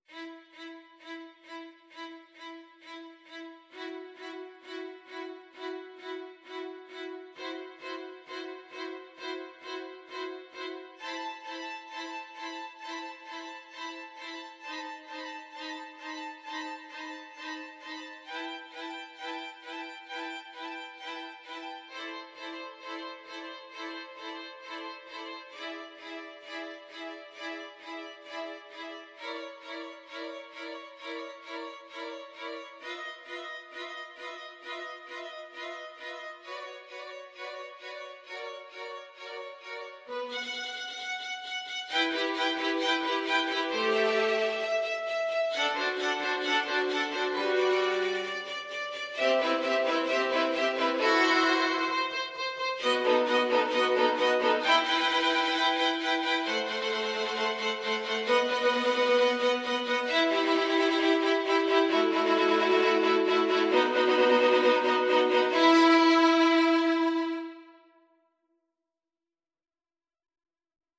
Allegro non molto =c.132 (View more music marked Allegro)
4/4 (View more 4/4 Music)
Violin Trio  (View more Easy Violin Trio Music)
Classical (View more Classical Violin Trio Music)